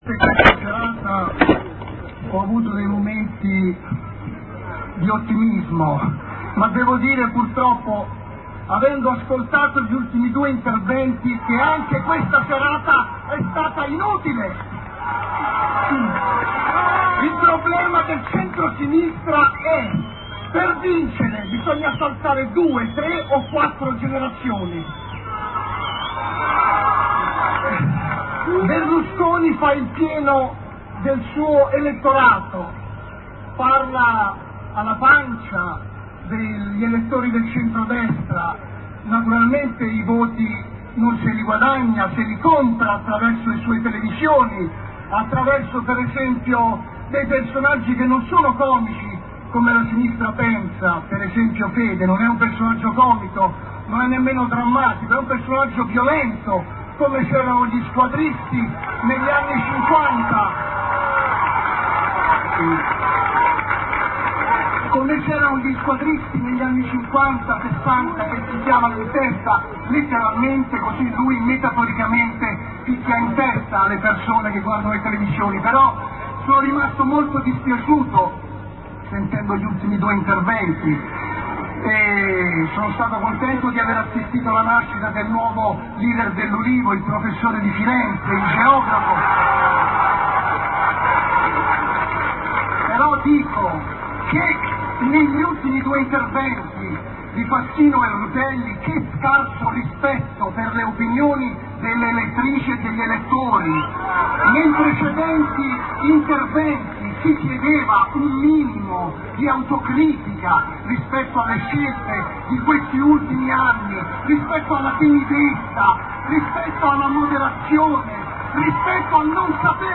L'intervento integrale di
ad una manifestazione dell'Ulivo febbraio 2002